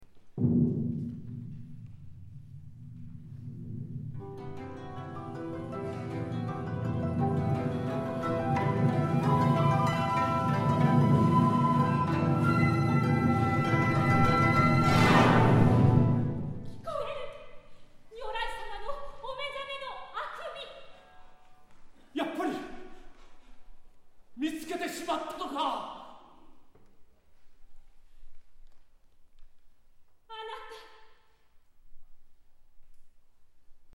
邦楽器による伝説舞台
合奏